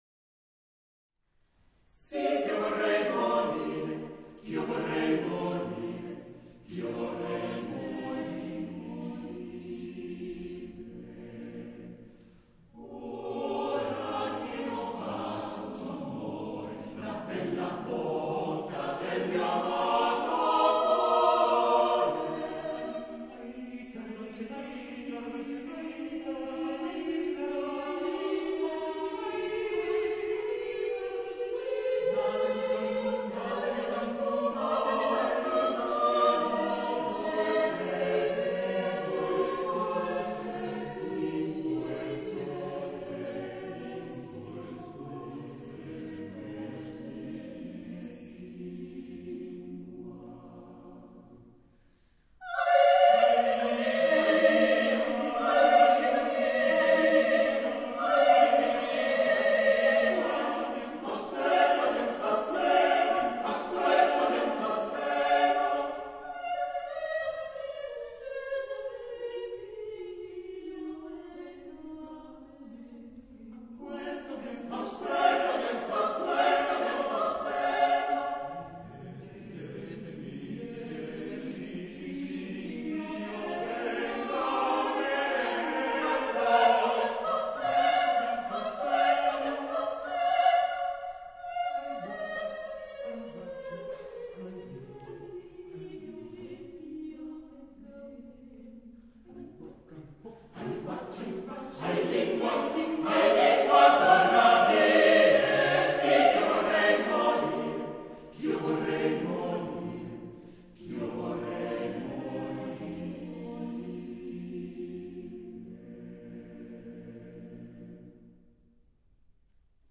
Genre-Style-Forme : Madrigal ; Profane
Type de choeur : SATTB  (5 voix mixtes )
Tonalité : la mineur
Réf. discographique : Internationaler Kammerchor Wettbewerb Marktoberdorf